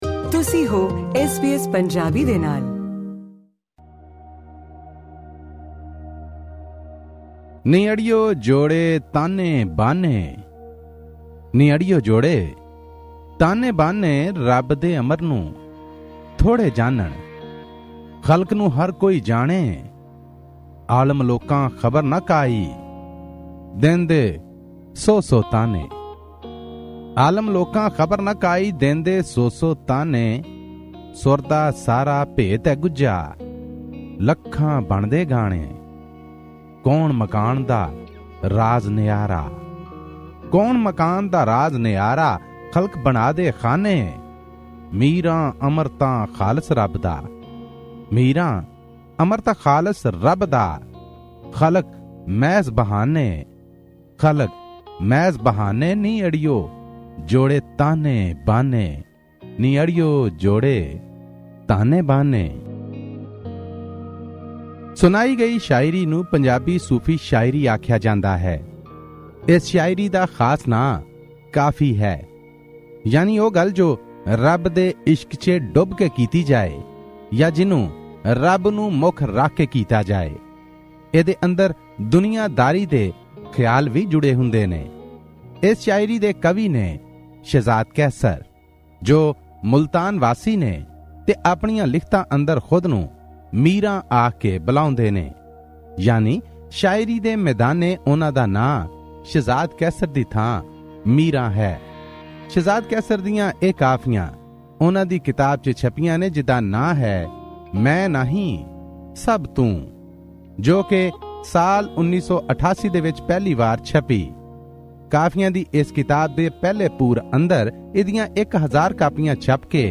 Pakistani Punjabi poetry book review: 'Main Nahin Sab Toon' by Shahzad Qaisar